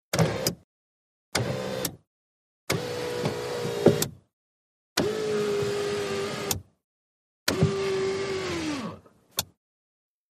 VEHICLES - AUTO ACCESSORIES: Electric window, close in jerks.